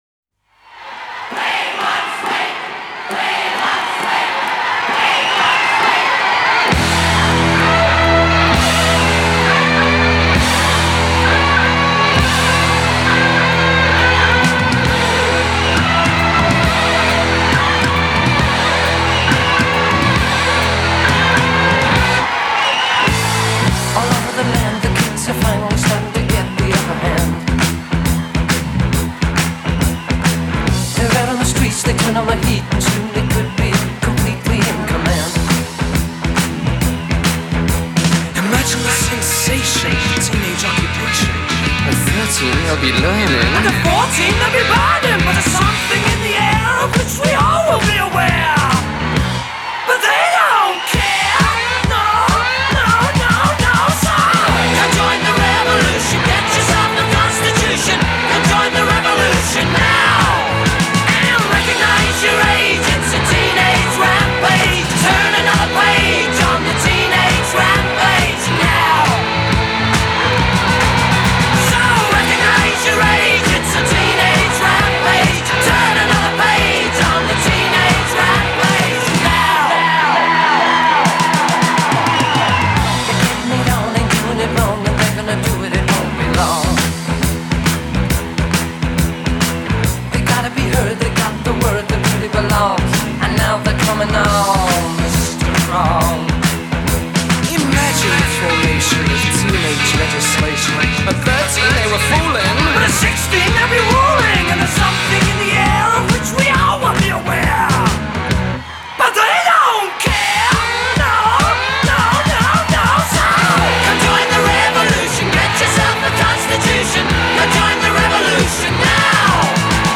британская рок-группа